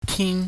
Cantonese Sounds-Mandarin Sounds
tin tian || dian* || mian*
tinHF.mp3